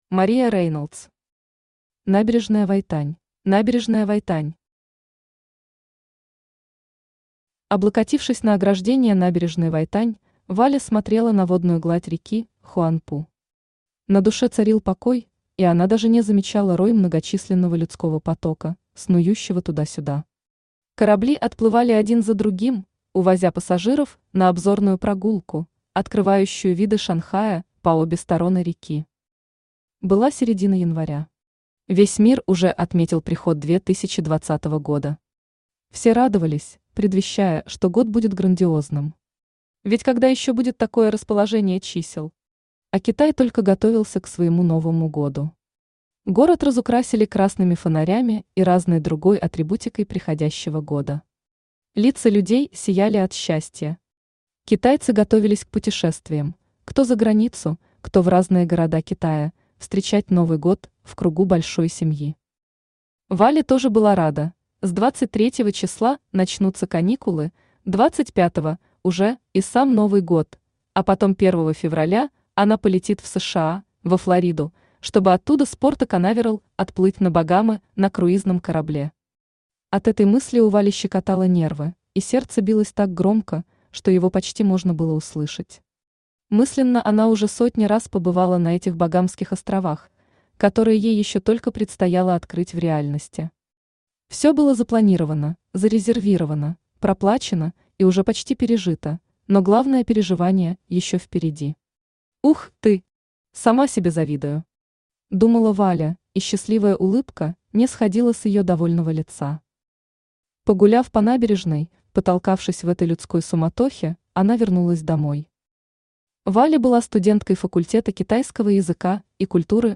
Аудиокнига Набережная Вайтань | Библиотека аудиокниг
Aудиокнига Набережная Вайтань Автор Мария Рейнолдс Читает аудиокнигу Авточтец ЛитРес.